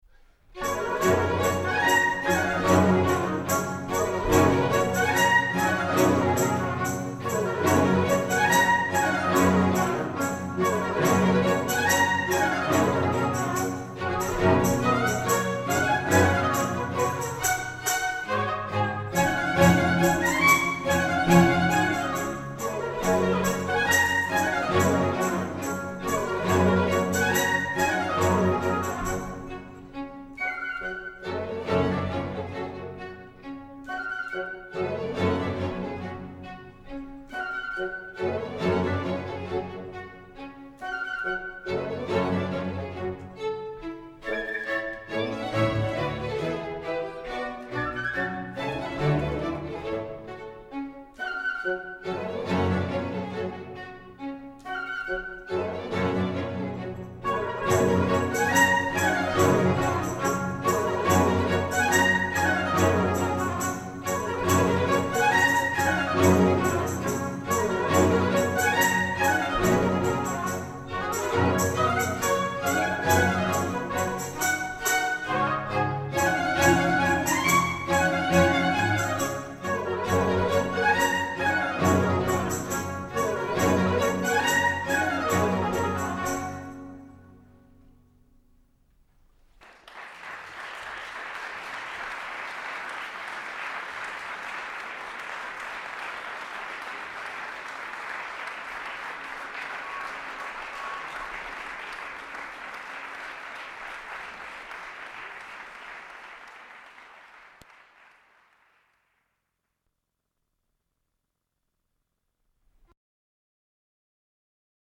Salle Paderewski – Casino de Montbenon